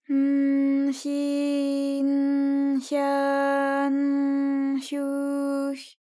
ALYS-DB-001-JPN - First Japanese UTAU vocal library of ALYS.
hy_n_hyi_n_hya_n_hyu_hy.wav